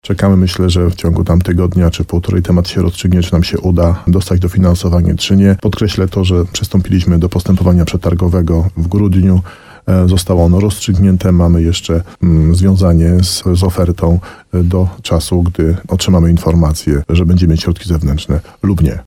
Starosta nowosądecki Tadeusz Zaremba mówił w programie Słowo za słowo na antenie RDN Nowy Sącz, że temat remontu obwodnicy znajduje się na liście rezerwowej zadań, które miałyby być dofinansowane z pieniędzy unijnych dysponowanych przez marszałka Małopolski.